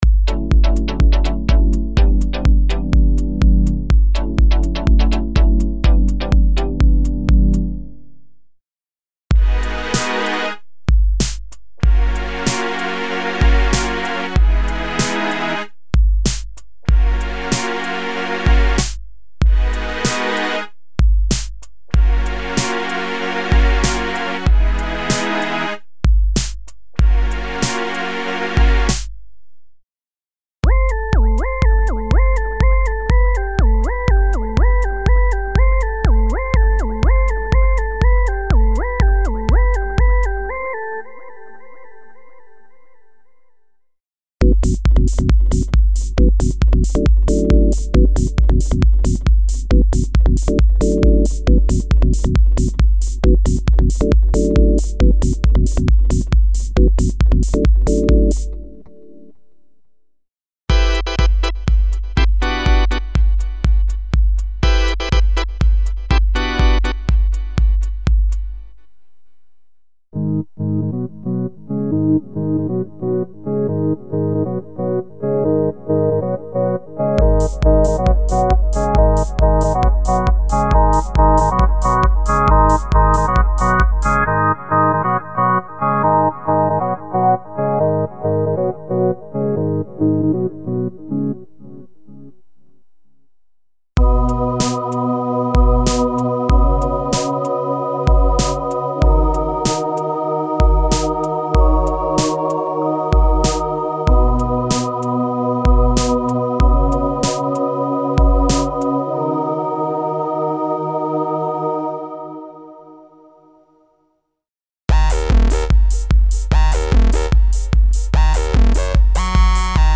Dance - original sound programs for modern dance music styles (filtered and experimental pads, el. strings, synth voices, piano & organ chords and tone intervals, hybrid basses, stacks, etc.).
Info: All original K:Works sound programs use internal Kurzweil K2600 ROM samples exclusively, there are no external samples used.